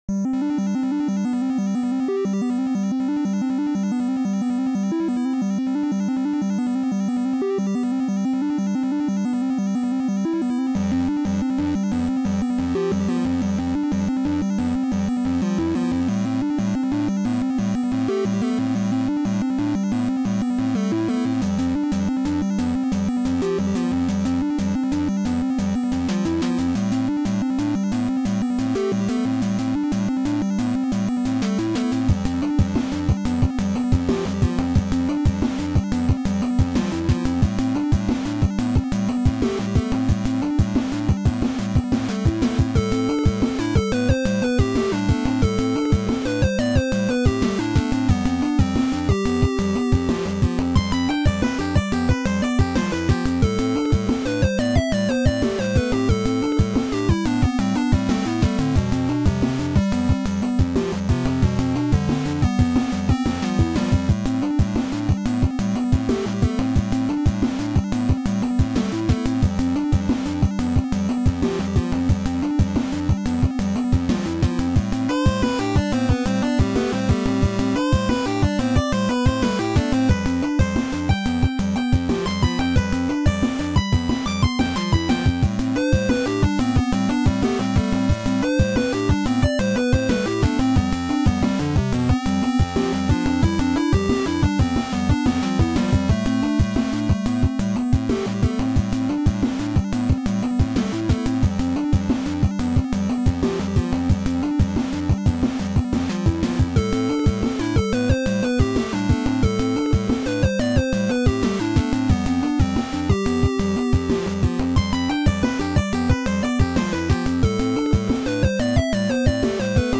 A port of my previous work to the 2A03 soundchip
completion_nes_2a03.ogg